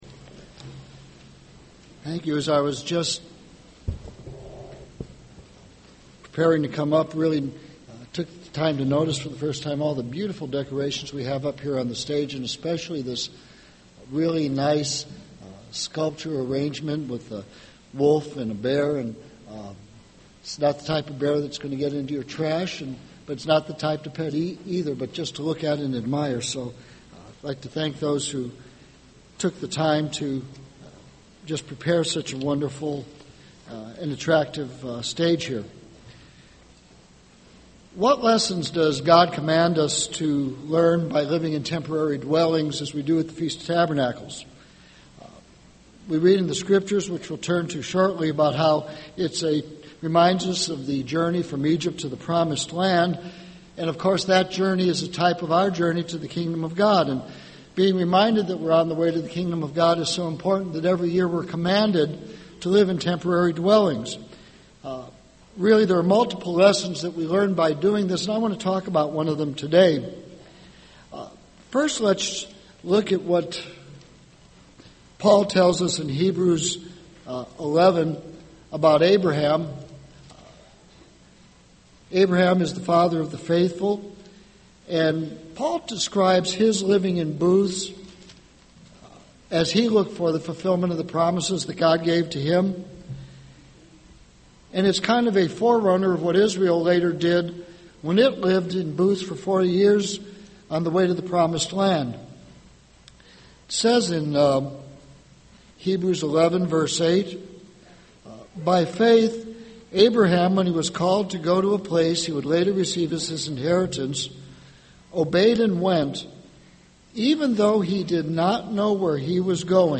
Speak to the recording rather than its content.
This sermon was given at the Gatlinburg, Tennessee 2011 Feast site.